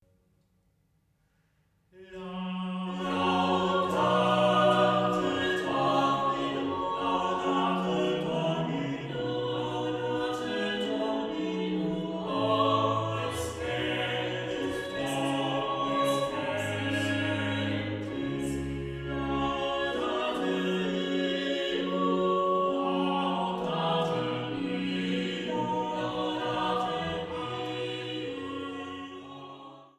Leitung und Orgel